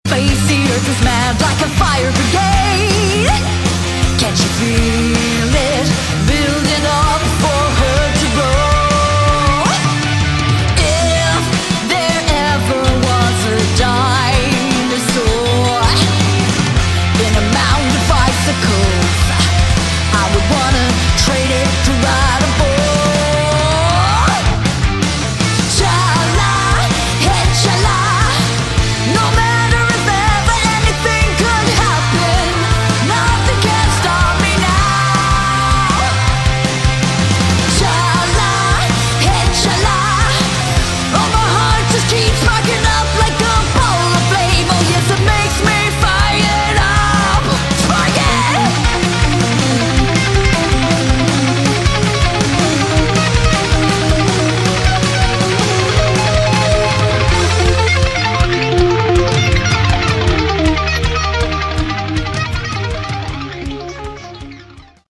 Category: Hard Rock/Punk Rock
vocals
drums
bass
guitar